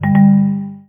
UI_Quit.wav